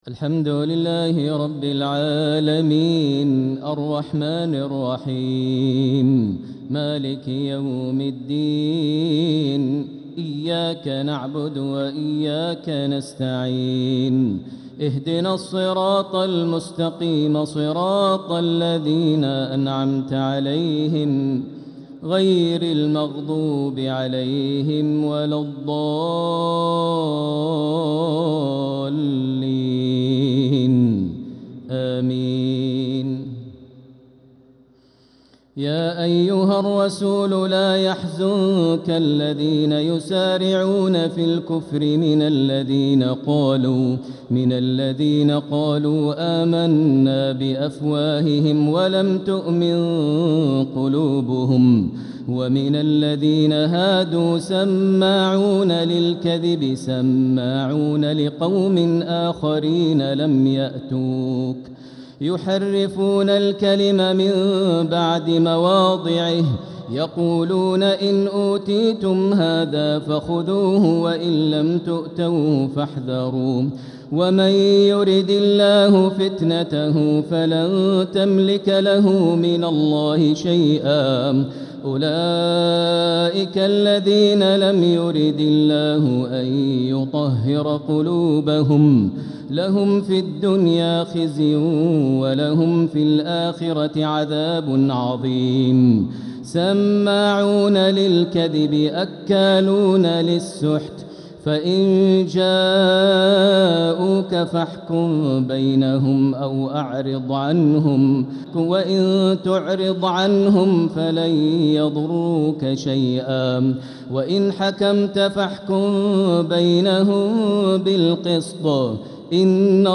تراويح ليلة 8 رمضان 1446هـ من سورة المائدة {41-81} Taraweeh 8th night Ramadan 1446H Surat Al-Maidah > تراويح الحرم المكي عام 1446 🕋 > التراويح - تلاوات الحرمين
Al-MuaiqlyTaraweeh.mp3